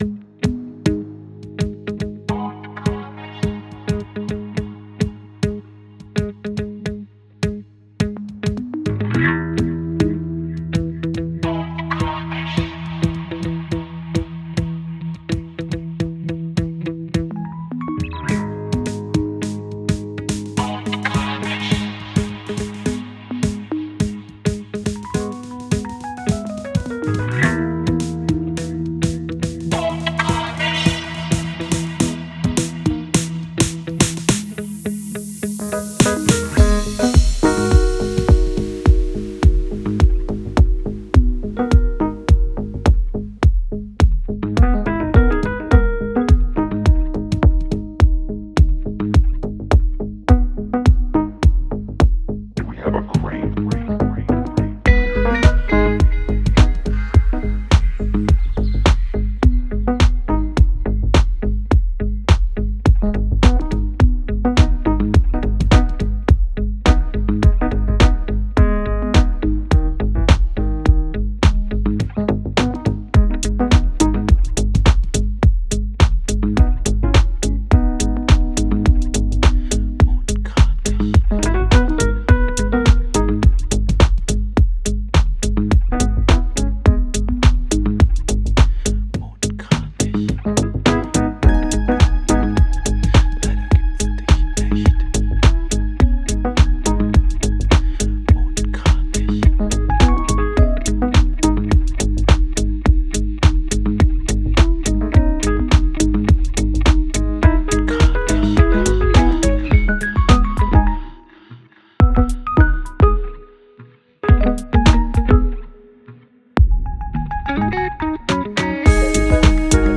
ok, dann hau ich jetzt noch den master vom letzten snarecut-mix raus.
aber bei angepasster lautheit könnte er sich durch etwas mehr klarheit bemerkbar machen.
-13.5 LUFS... :D